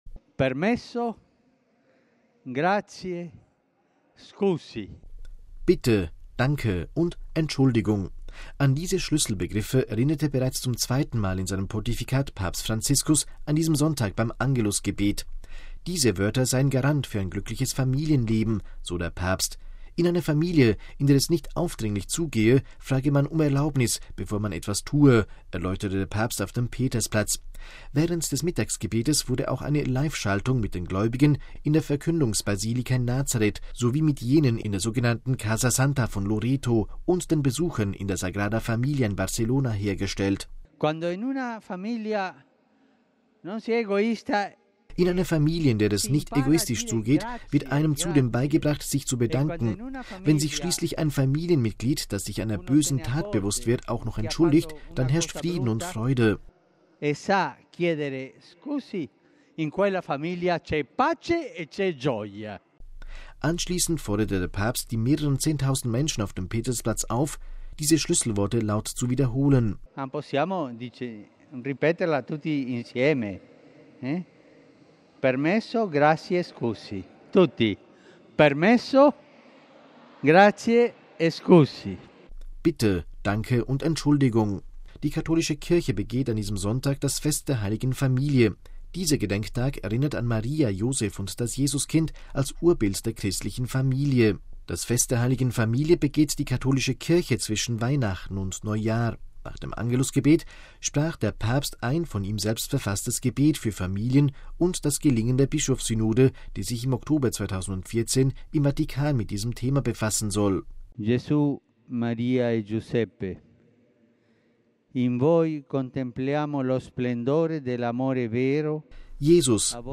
Anschließend forderte der Papst die mehreren zehntausend Menschen auf dem Petersplatz auf, die Schlüsselworte laut zu wiederholen.